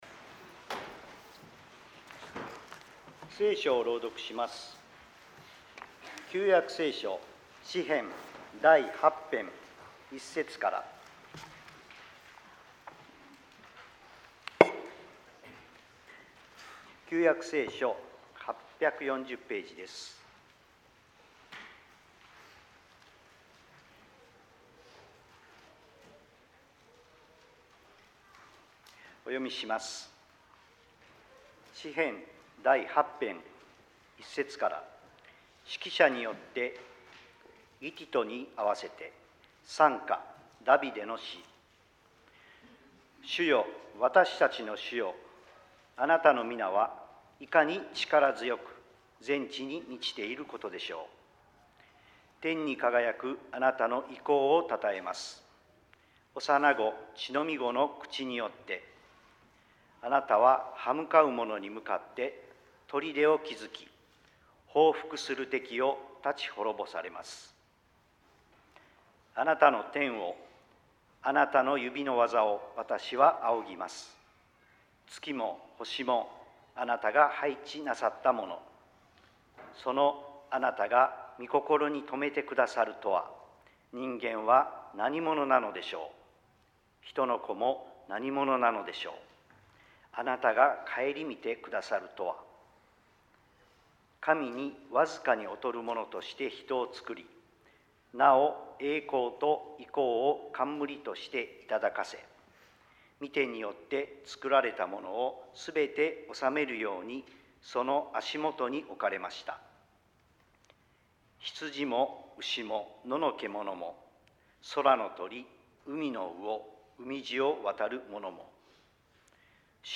説 教 「誰が一番偉いか」